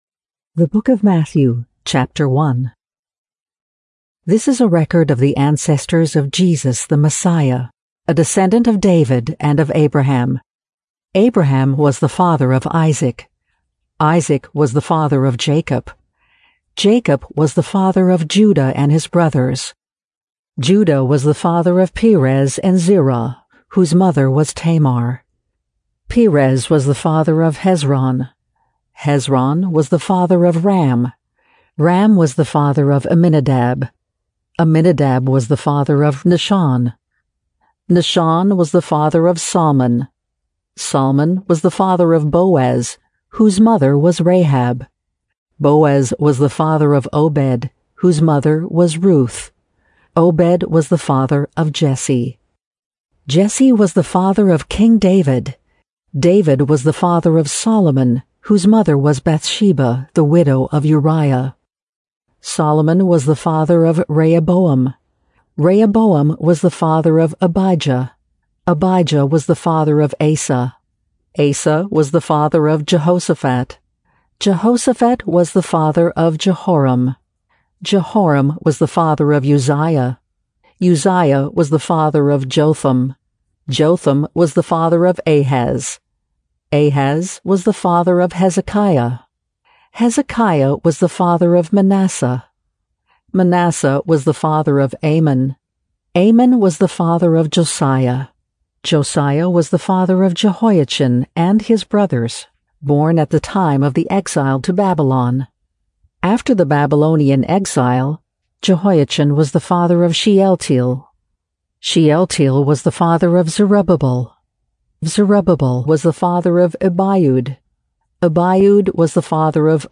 English NLH MP3 Bible - Womans Voice
New Living Translation - NT Non-Drama - Woman's Voice